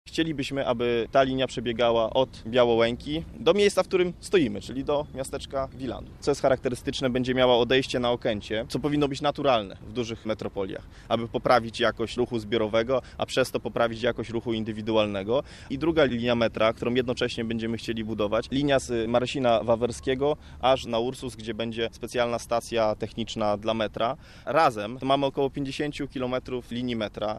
Patryk Jaki na konferencji prasowej zapowiedział, że jeżeli zostanie prezydentem stolicy od razu rozpocznie budowę trzeciej i czwartej linii jednocześnie.